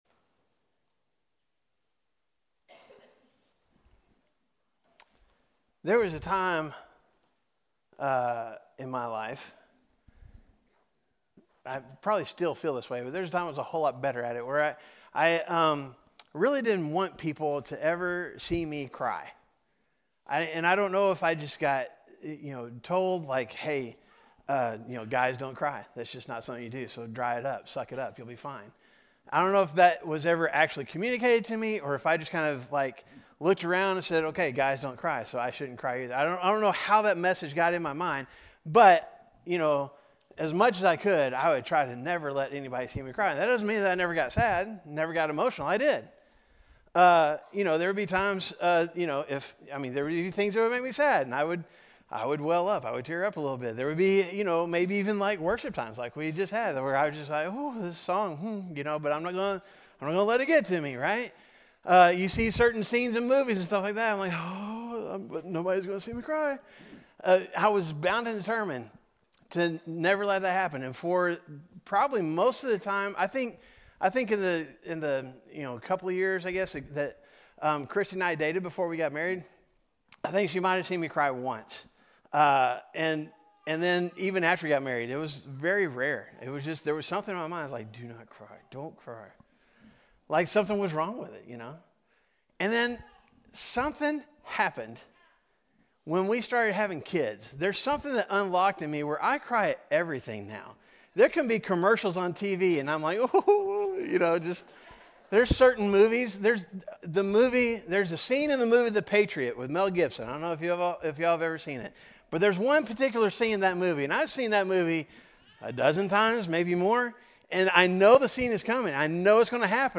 A message from the series "Mistaken Truth."